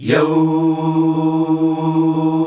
YOOOOH  E.wav